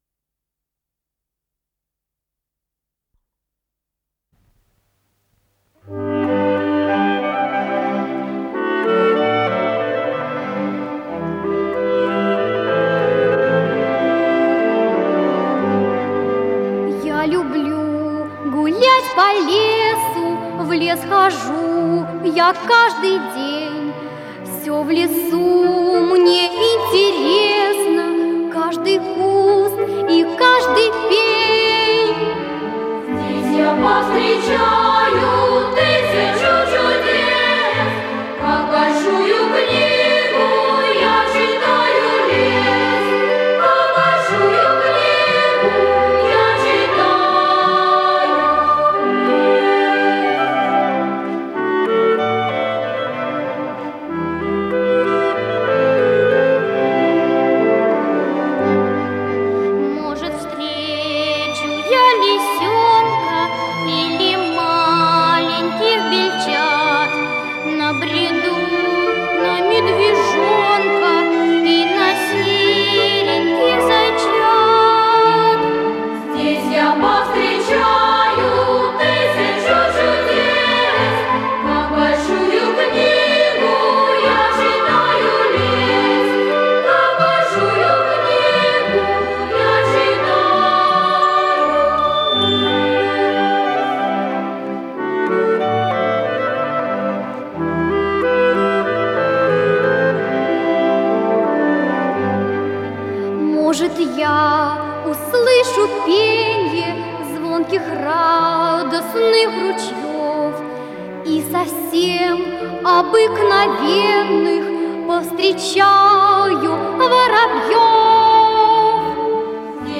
с профессиональной магнитной ленты
АккомпаниментИнструментальный ансамбль
ВариантДубль моно